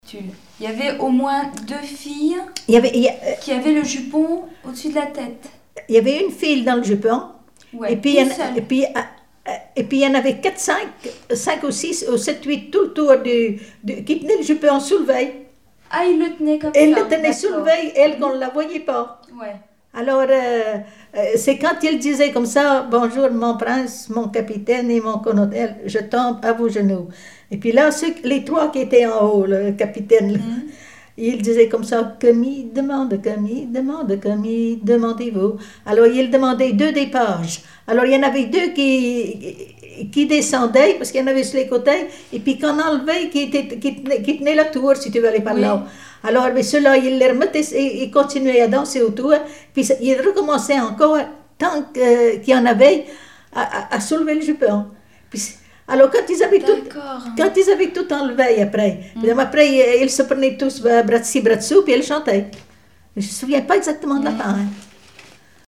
Témoignages sur les folklore enfantin
Catégorie Témoignage